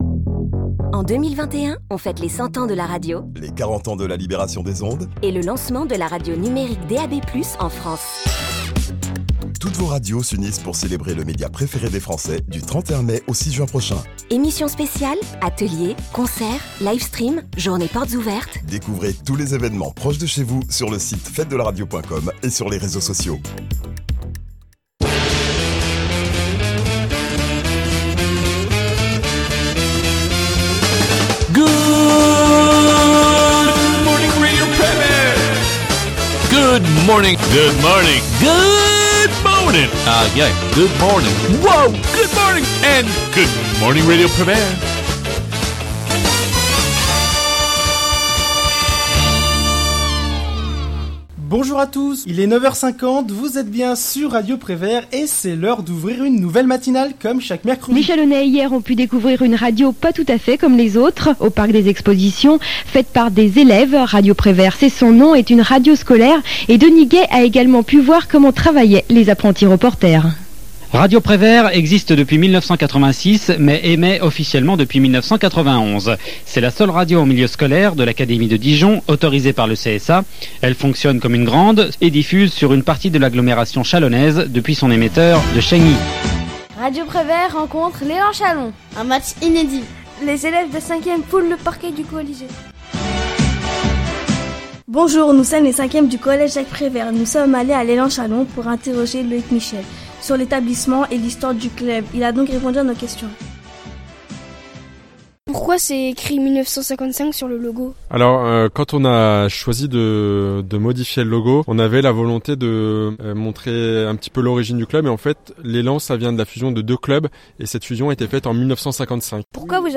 Retrouvez dans ce medley des extraits d’émissions, de reportages et d’interviews.